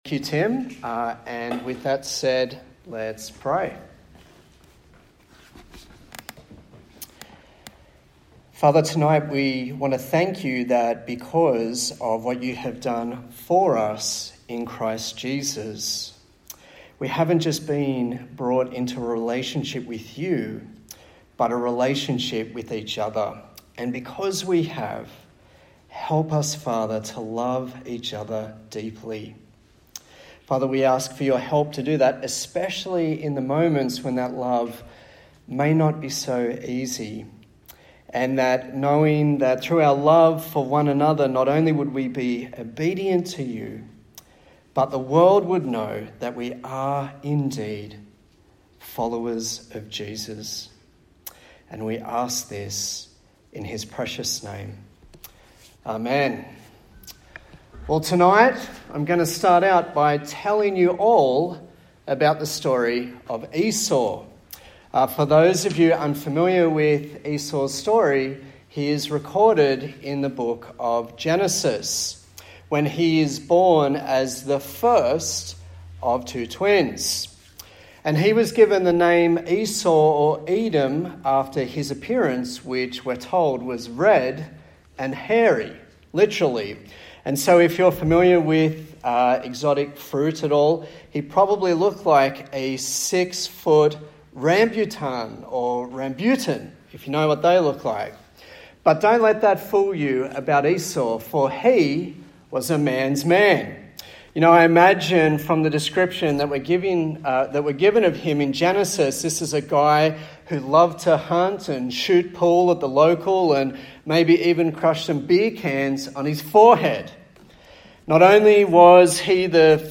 A sermon in the series on the book of Galatians